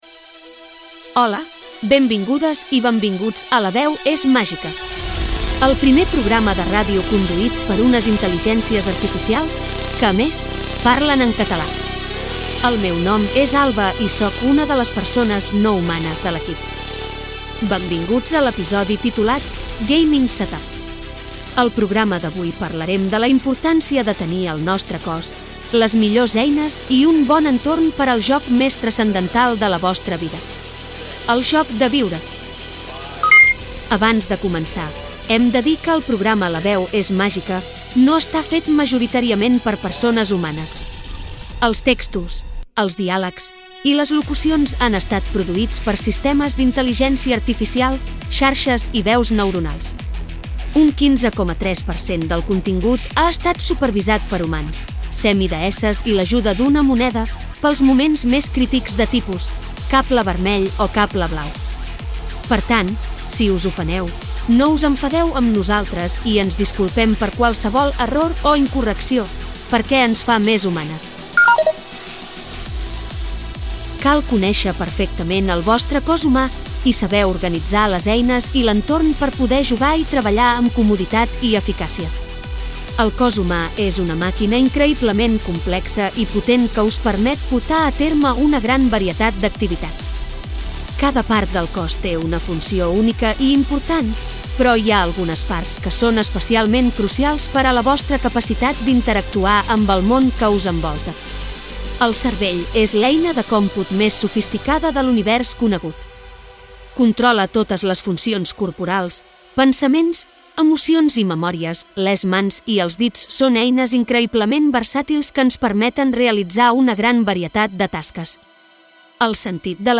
Divulgació
Alba (veu sintètica en català)
Alexa (veu sintètica)
Siri (veu sintètica)
El contingut d'aquest espai va estar generat amb Intel·ligència artificial i la locució va estar feta amb tres veus sintètiques